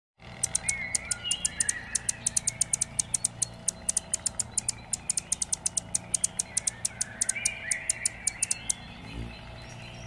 They use a series of chirps and calls, and during mating season, the males perform elaborate aerial displays.
Ruby-throated Hummingbird Sound
Humming-bird-sound.mp3